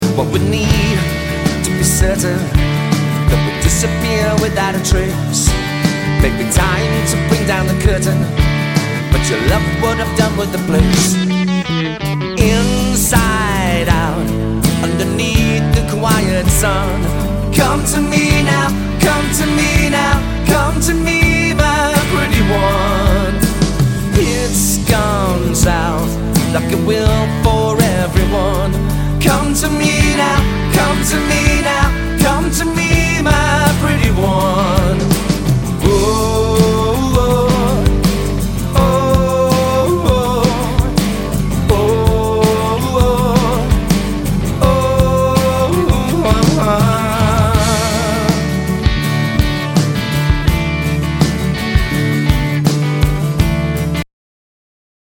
abotsa, gitarrak eta teklatuak
baxua
bateria, perkusioak eta abotsak